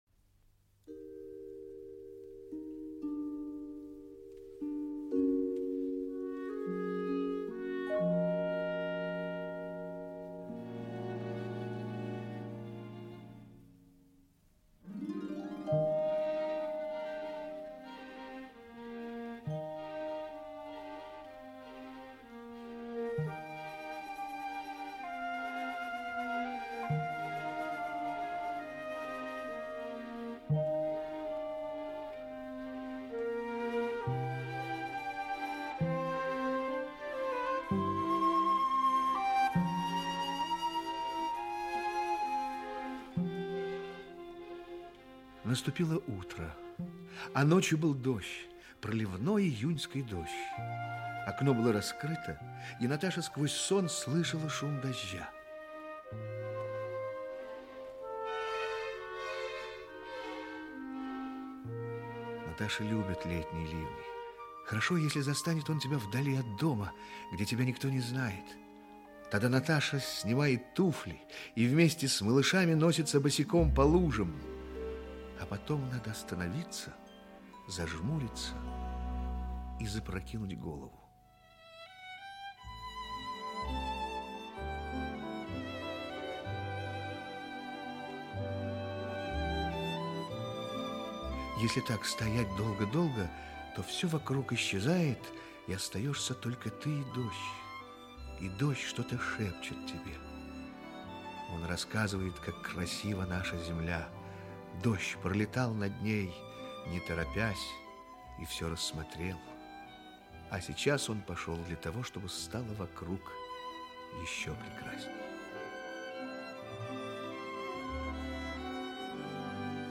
Aудиокнига Наташа.